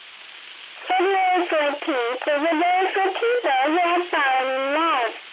05_fairy_radio.wav